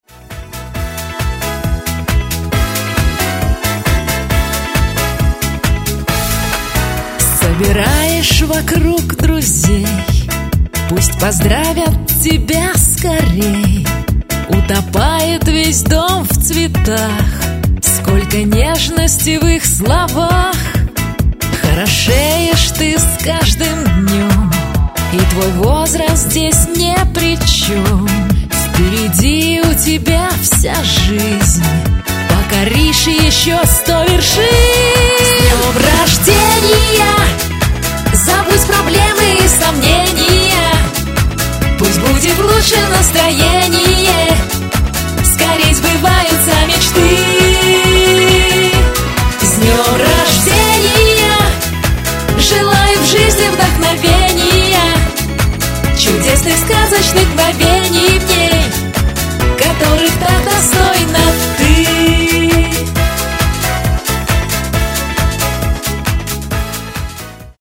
Музыкальное голосовое поздравление для женщины в SMS